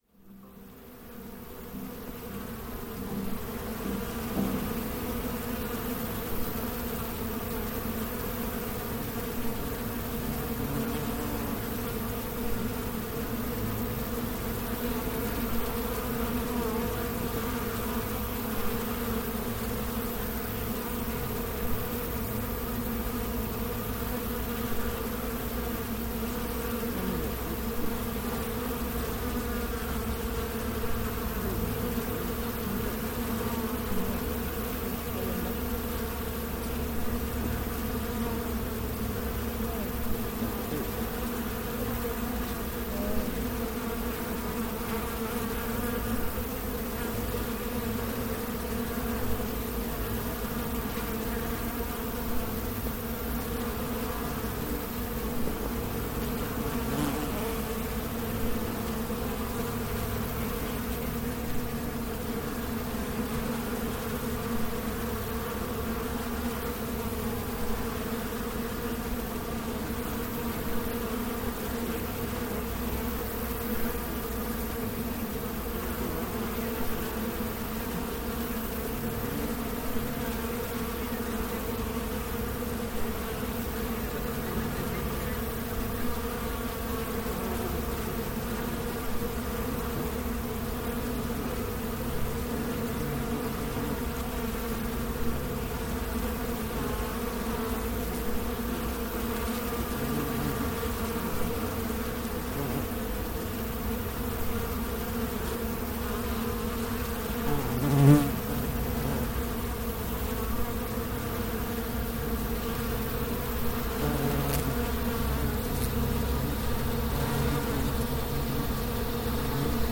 A 20-minute excerpt from our sound installation at the Kymmata multimedia exhibition in Oxford, January 2015.